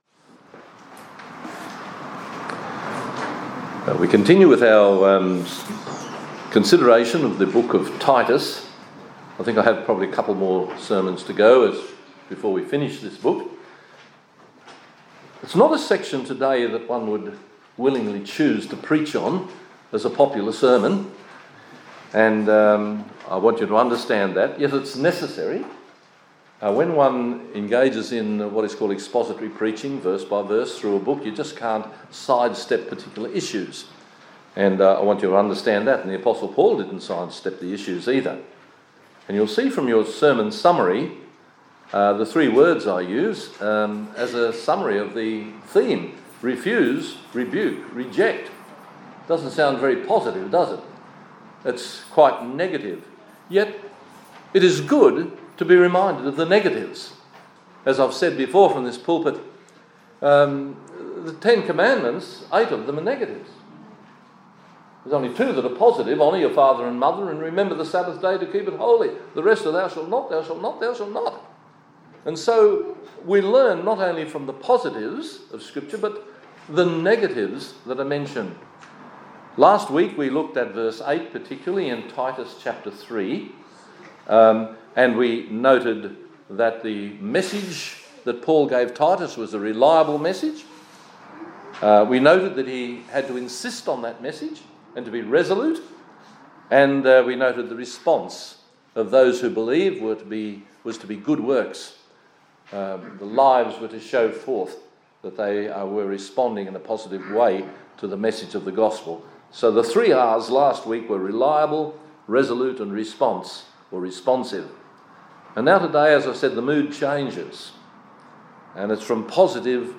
2019-11-03 - "Refuse, Rebuke, Reject" Acts 28:17-32; Titus 3:8-11. Sermon